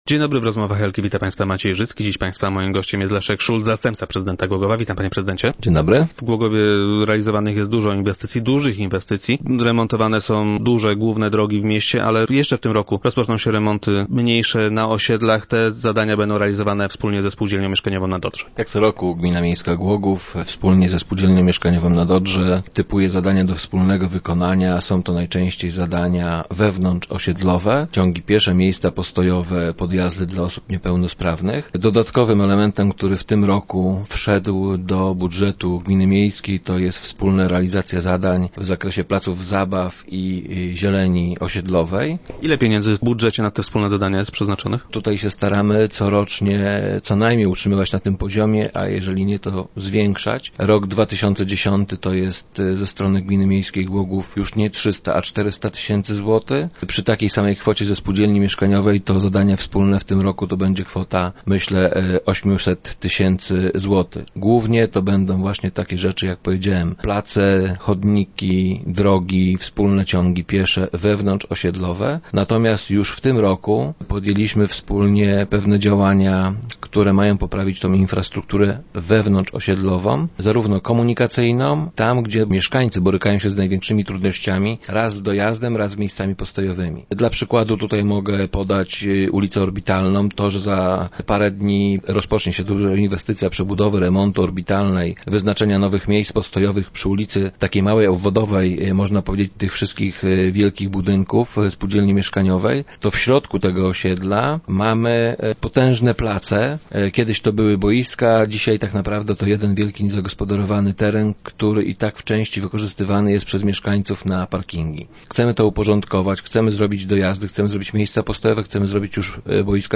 Wspólnie z ze spółdzielnią mieszkaniową Nadodrze, gmina zamierza zmodernizować głogowskie osiedla. - Z roku na rok przeznaczamy na to coraz więcej pieniędzy - informuje Leszek Szulc, zastępca prezydenta Głogowa, który był dziś gościem Rozmów Elki.